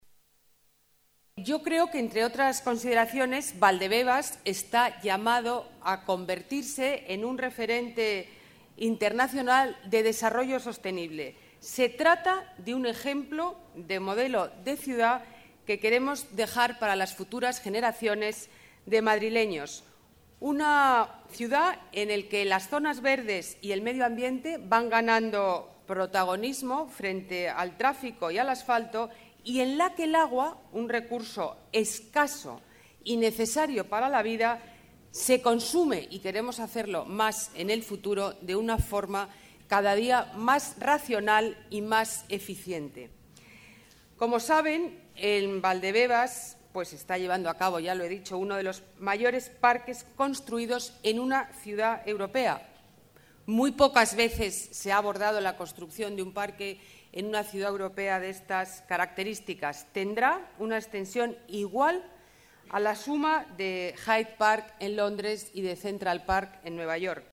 Nueva ventana:Declaraciones de la delegada de Medio Ambiente, Ana Botella: Valdebebas, modelo de ciudad con agua regenerada